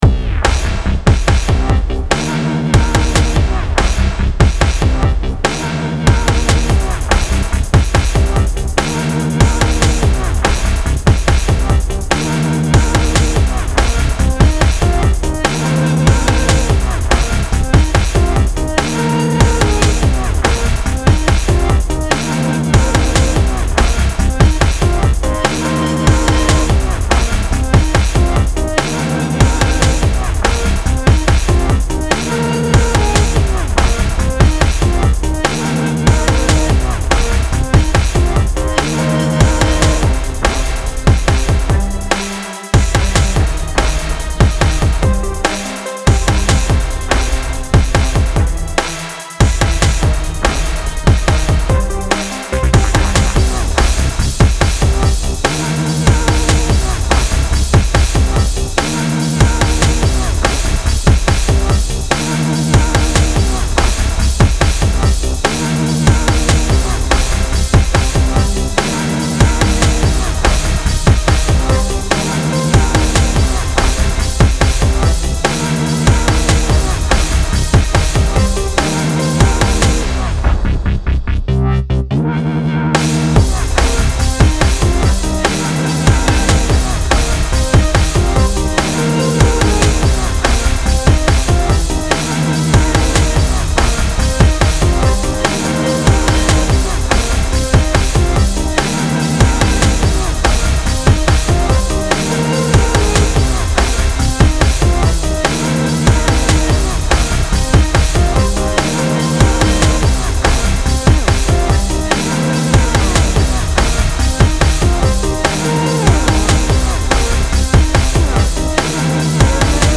Style Style EDM/Electronic
Mood Mood Aggressive, Driving, Intense
Featured Featured Bass, Drums, Strings
BPM BPM 144